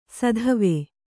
♪ sadhave